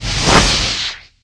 immolatorIgnite.ogg